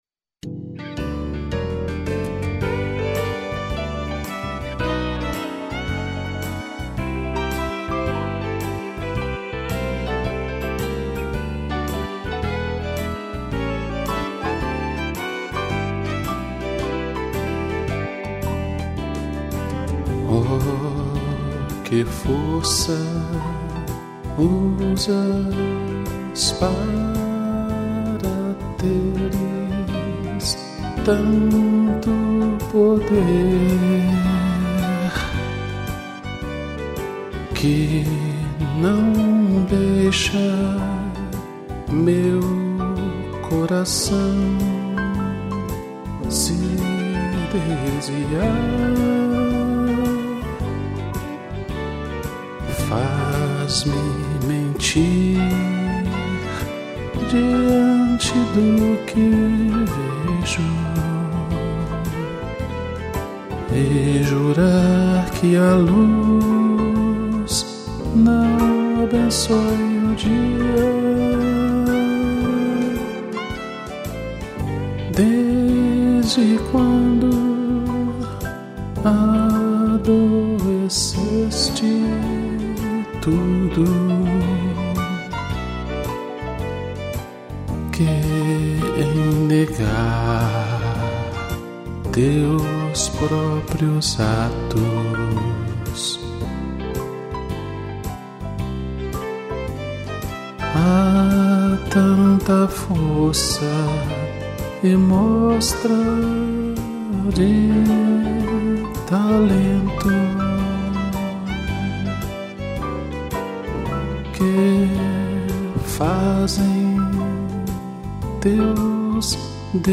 Voz e violão
piano, violino e sax